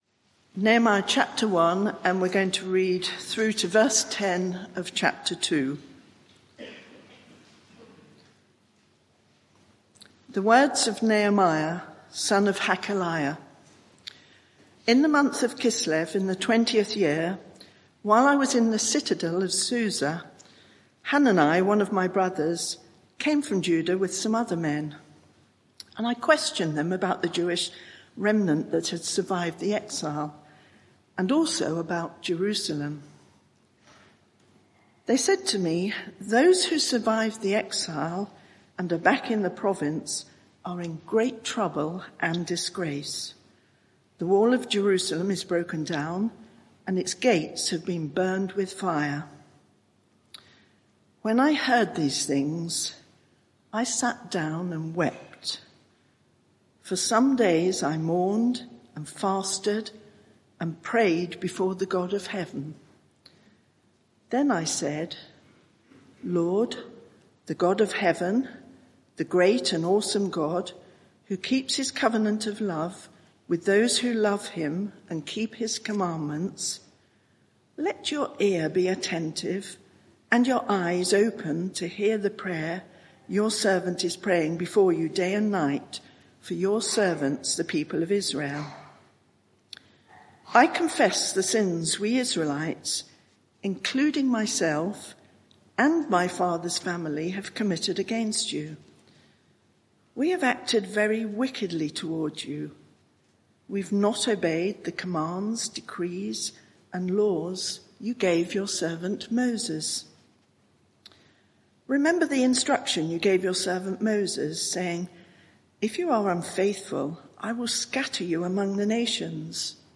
Media for 11am Service on Sun 14th Apr 2024 11:00 Speaker
Sermon (audio) Search the media library There are recordings here going back several years.